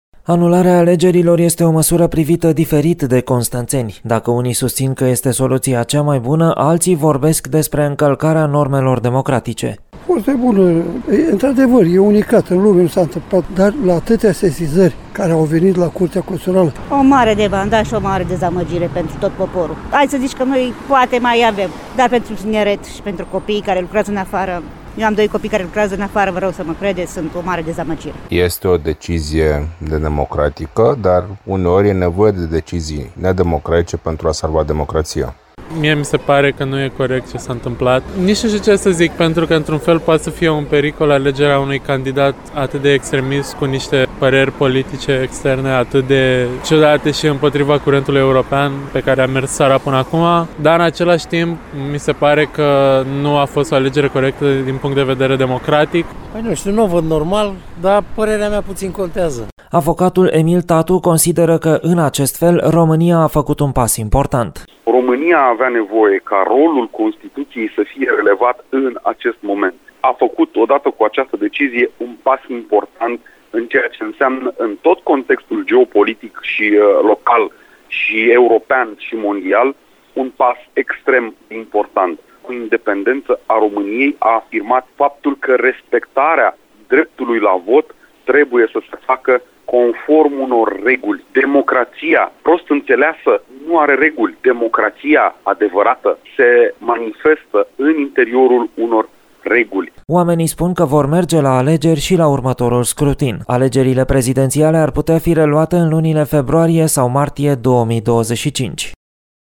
Să-i ascultăm pe acești constănțeni